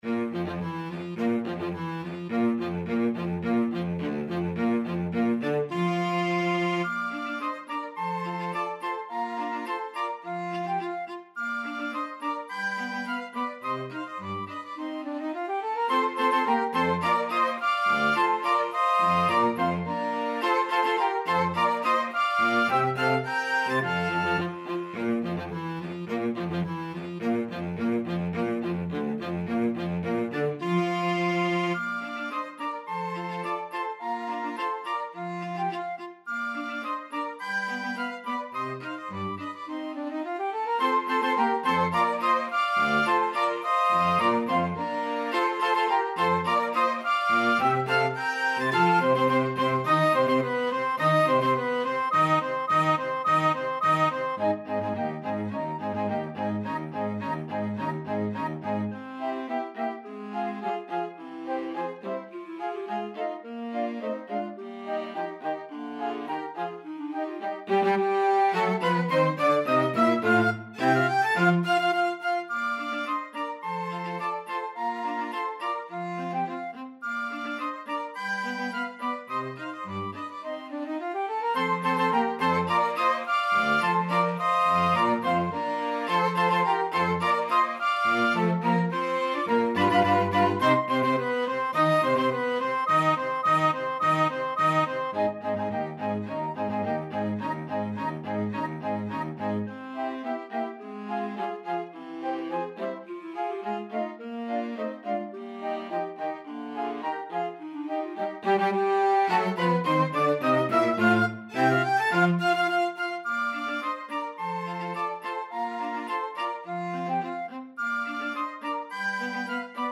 Flute
Clarinet
ClarinetBaritone Saxophone
2/4 (View more 2/4 Music)
Allegro = 106 (View more music marked Allegro)